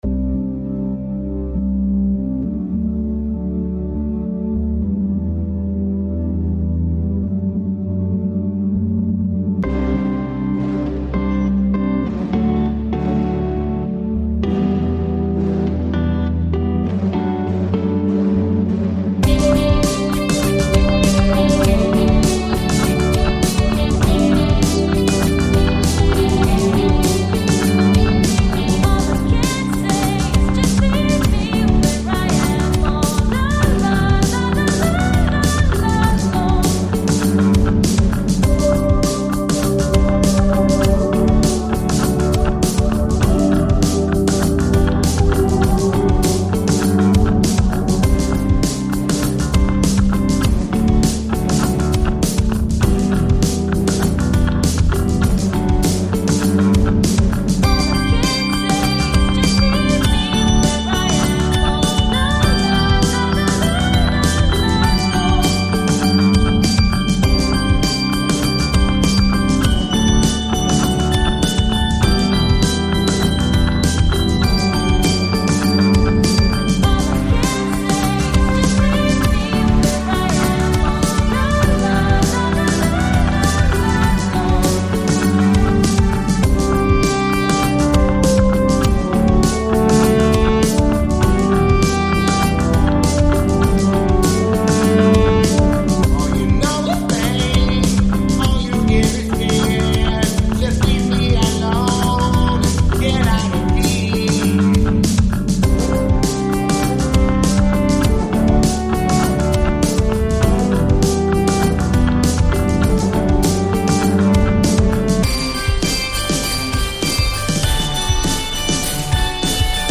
So I made my Synthesized voice do it.
I didn't expect the beat when it dropped.
I love all the layers here.